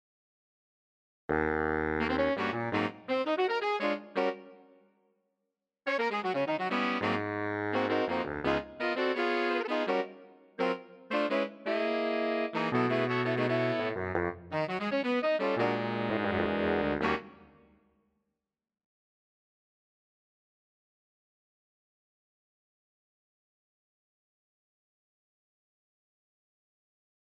I ran this through Garritan JABB 3.
Only custom expression map stuff in this case…I added CCs to apply falls and doits for the trumpets. The rest of winds/brass just CC1 dynamics.
To get ‘scoops’ for these saxes and falls/doits for the Trombones, I used the line tool to quickly draw pitch bends in on controller lanes.
I am using a touch on the FX Send on Dorico’s Mixer from Reverence, the Large Stage preset with no further user tweaks.
Saxes…